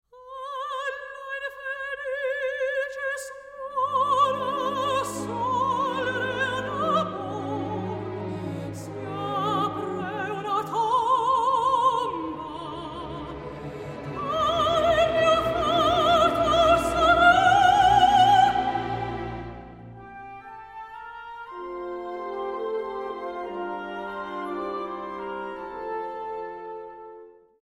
Rare French and Italian Opera Arias
Soprano
Released in stunning Super Audio CD surround sound.